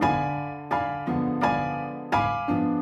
Index of /musicradar/gangster-sting-samples/85bpm Loops
GS_Piano_85-D1.wav